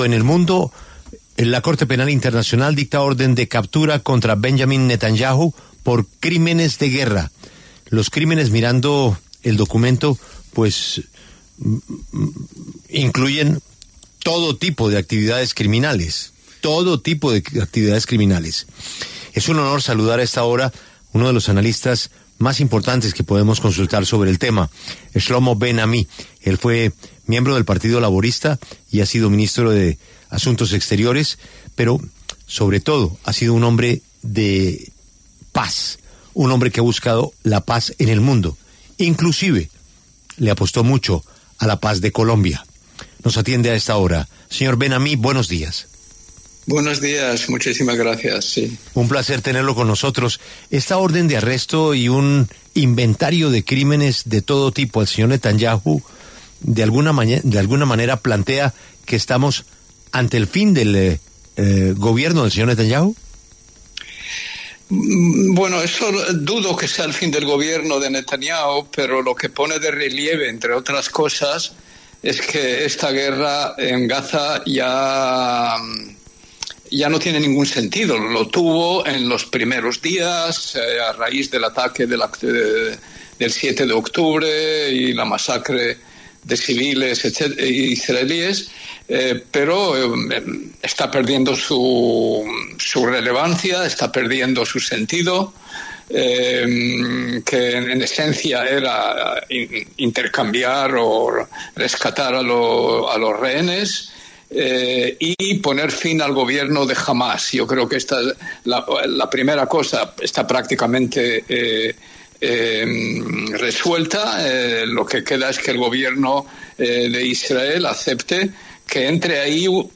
Shlomo Ben Ami, exministro de Relaciones Exteriores de Israel, conversó con La W sobre la orden de captura dictada por la CPI contra Benjamín Netanyahu por crímenes de guerra.